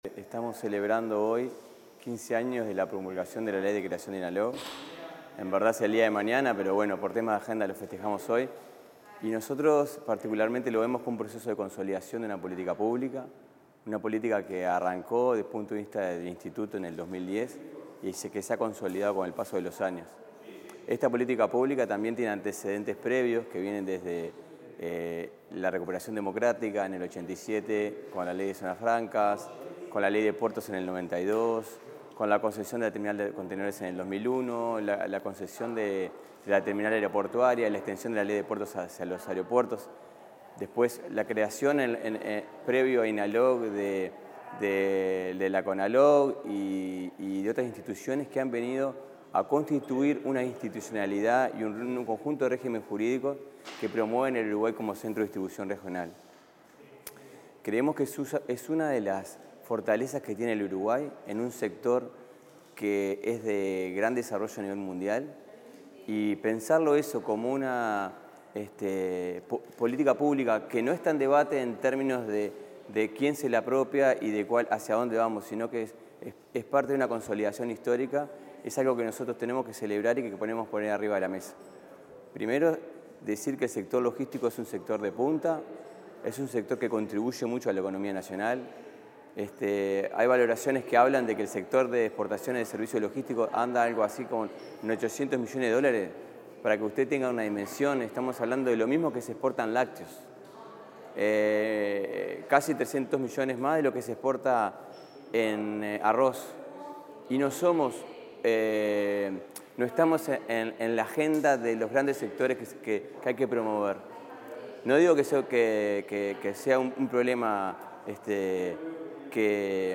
Declaraciones del presidente de Inalog, Jerónimo Reyes
El presidente del Instituto Nacional de Logística (Inalog), Jerónimo Reyes, diálogo con los medios de prensa tras la ceremonia conmemorativa del 15.°